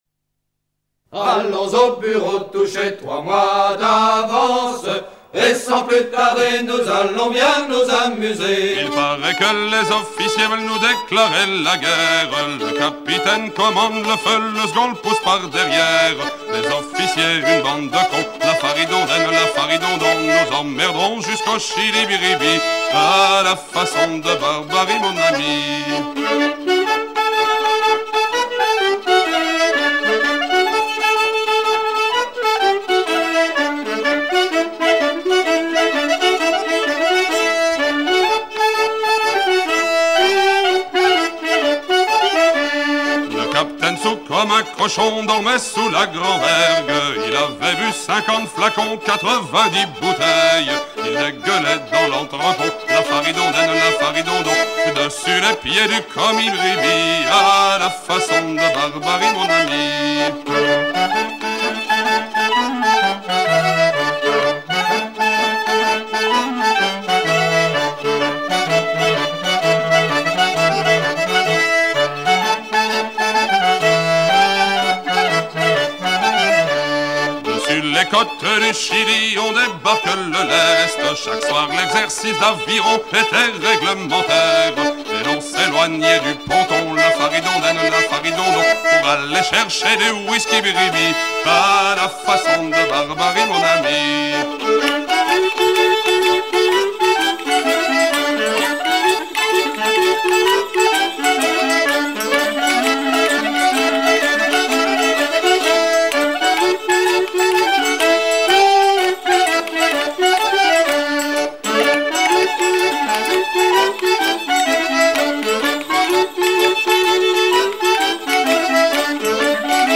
Fonction d'après l'analyste gestuel : à virer au cabestan ;